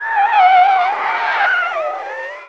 tyre_skid_05.wav